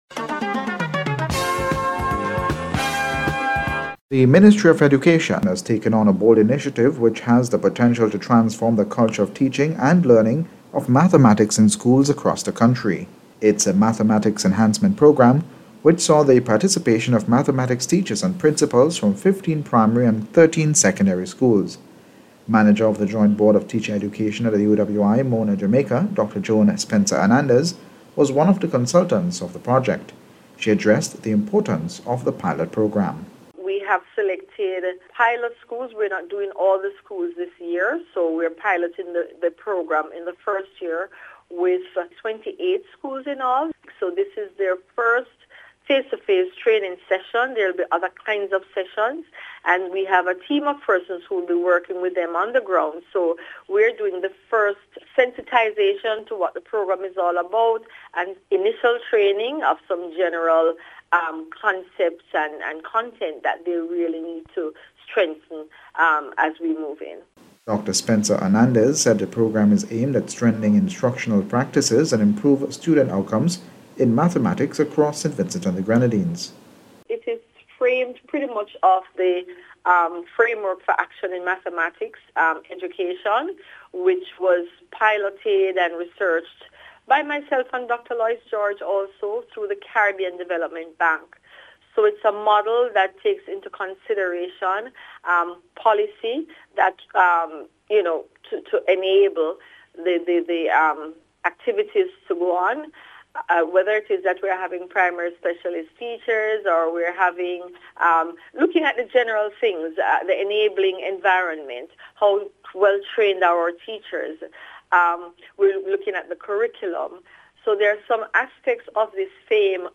NBC’s Special Report- Thursday 4th Spetember,2025